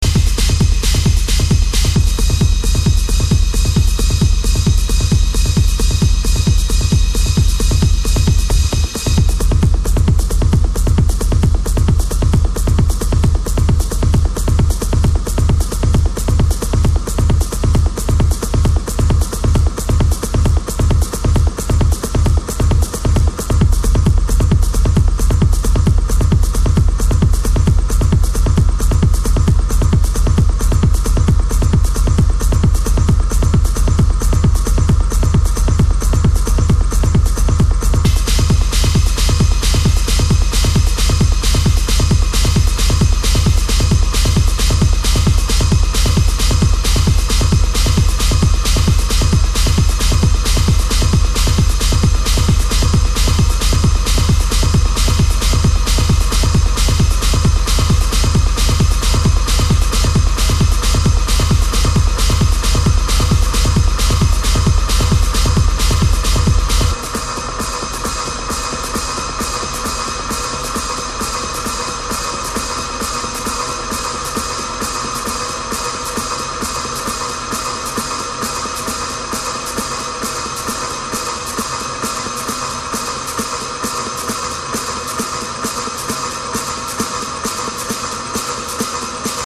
Big pounding techno.